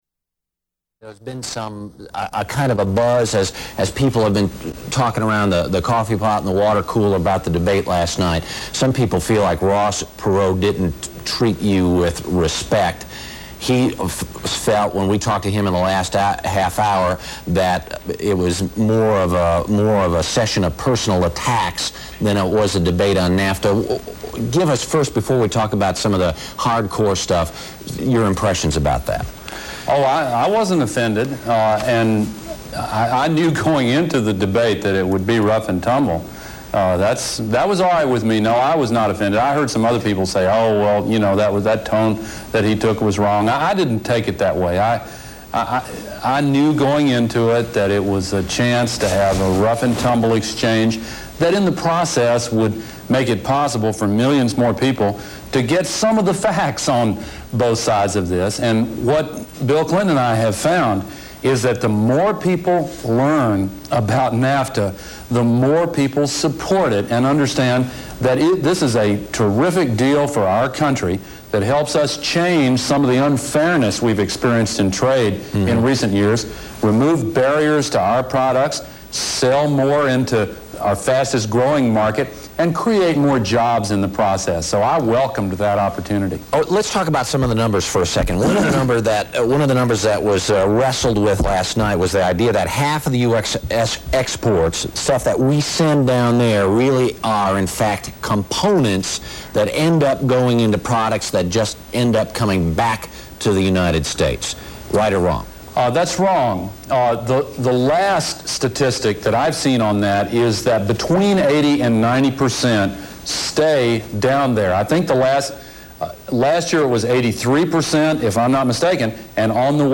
Broadcast on CBS-TV, Nov. 9, 1993.